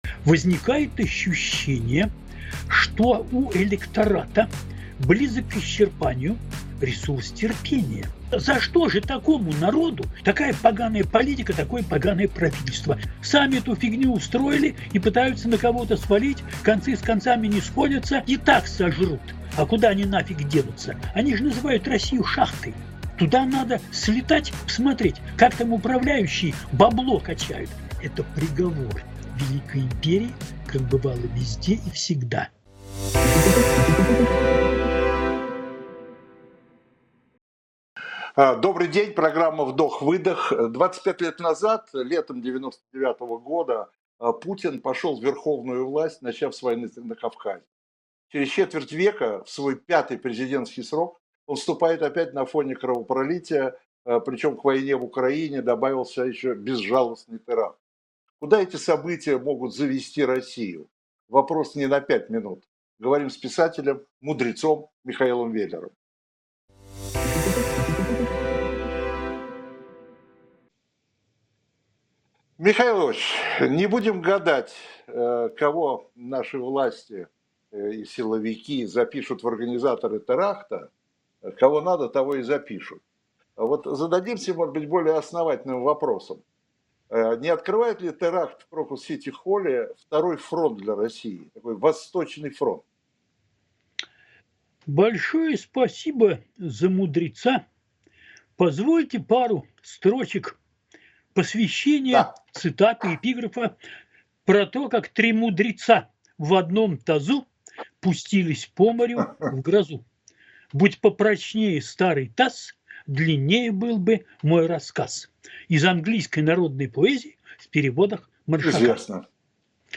Эфир ведёт Виталий Дымарский.